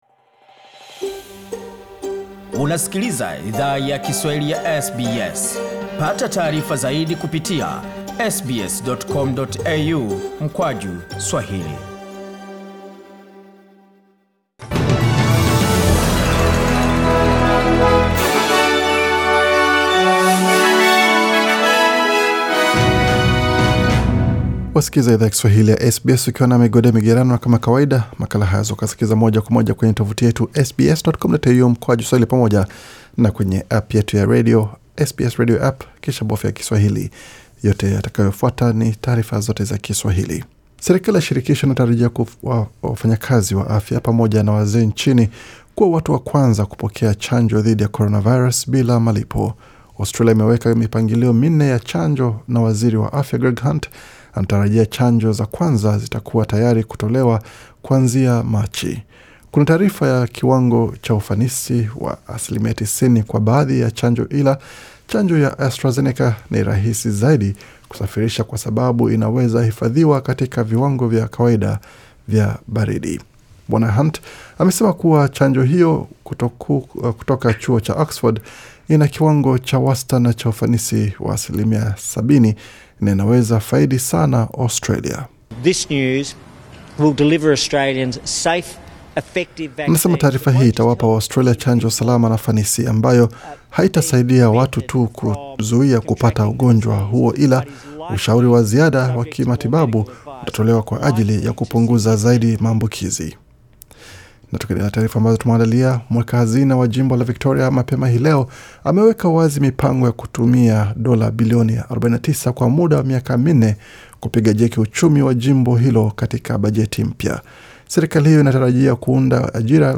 Taarifa ya habari 24 Novemba 2020